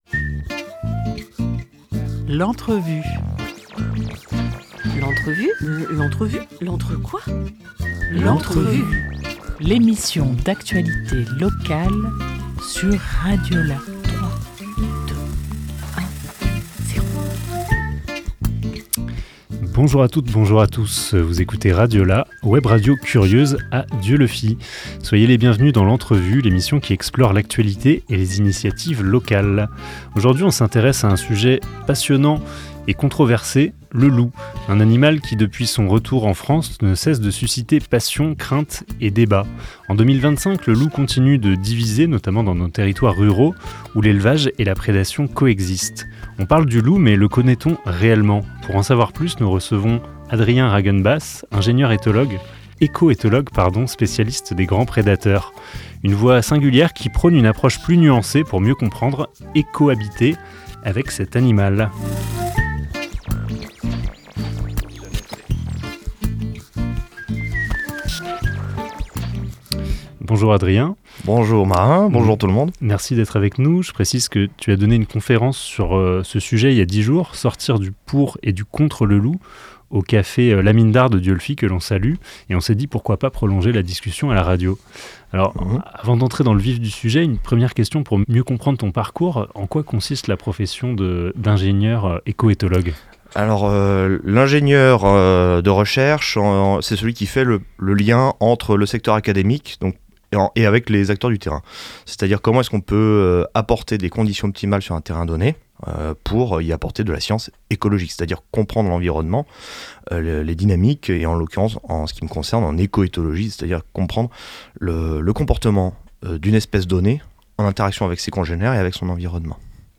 18 février 2025 11:33 | Interview